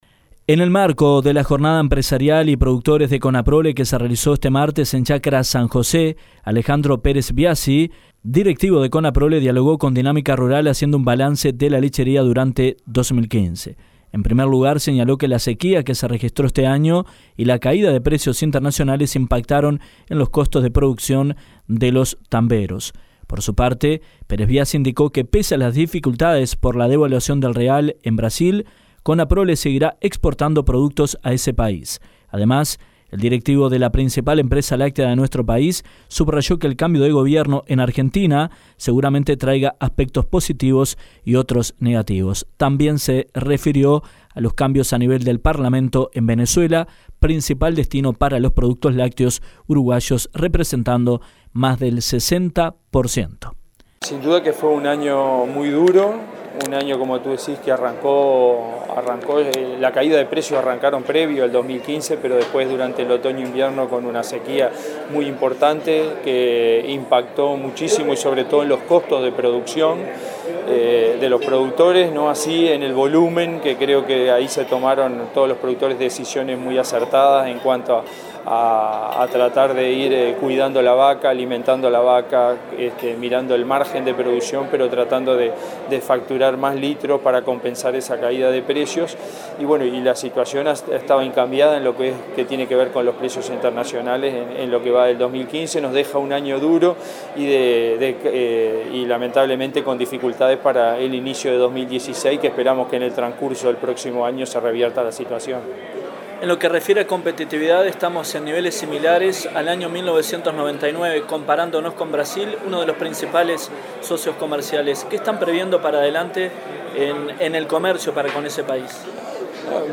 directivo de la cooperativa en entrevista con Dinámica Rural sobre la actual coyuntura del sector.